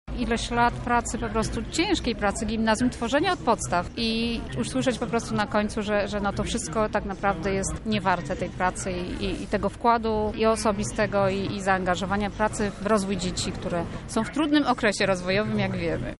Pod Urzędem Wojewódzkim odbyła się dziś pikieta nauczycieli.
O swoich obawach dotyczących zlikwidowania gimnazjów mówi reprezentantka grona nauczycielskiego: